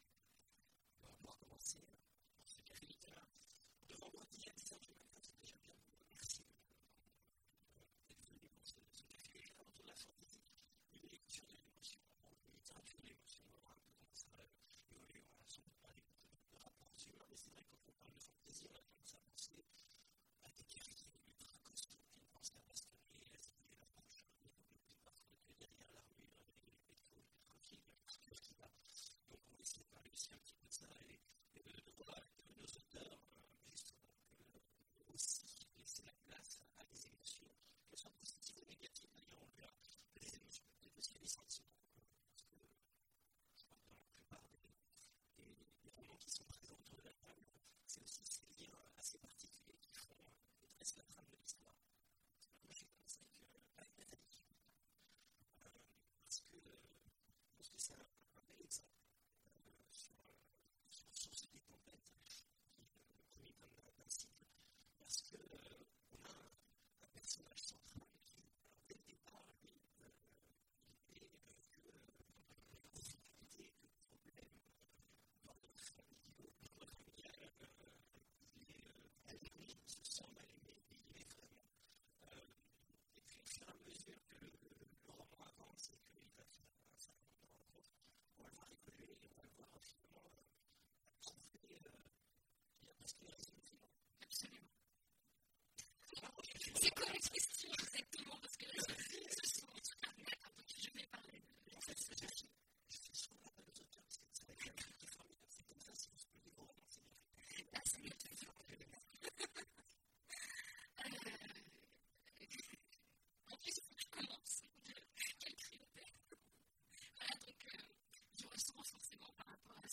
Imaginales 2016 : Conférence La Fantasy…